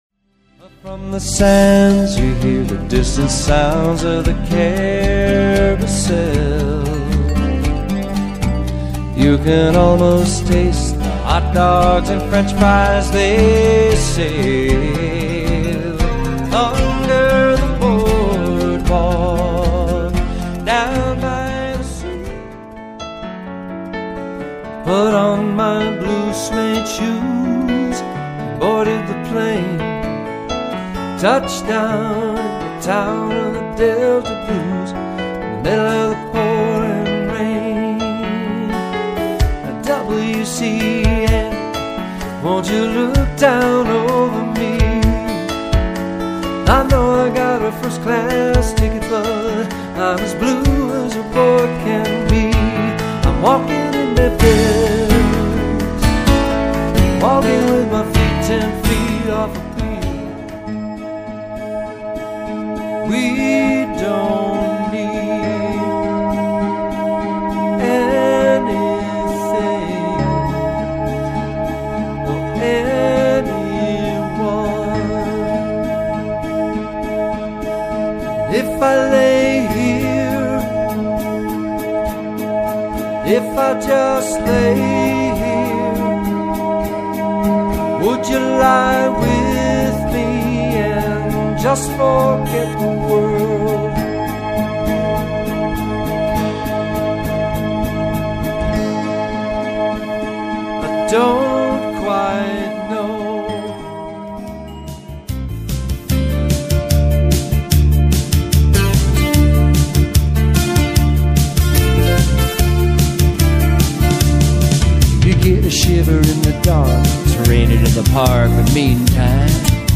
Singer, Guitarist, Entertainer.